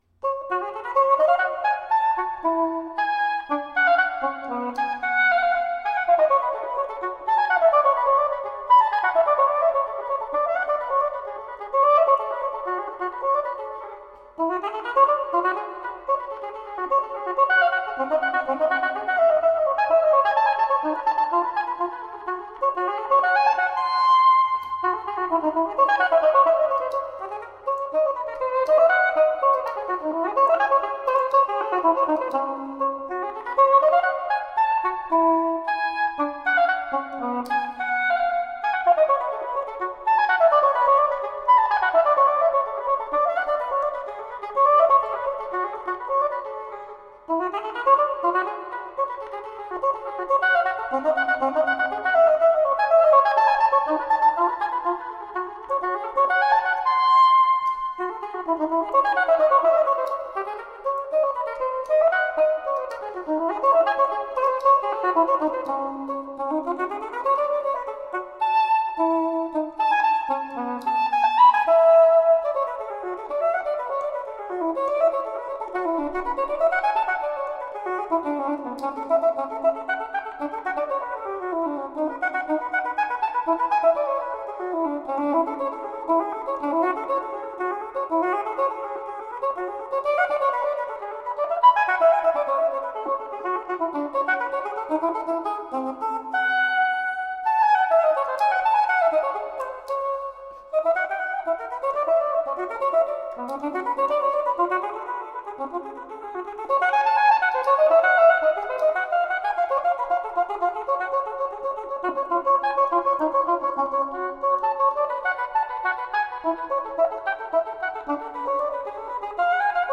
Baroque oboist extraordinaire.
Classical, Chamber Music, Baroque, Instrumental
Oboe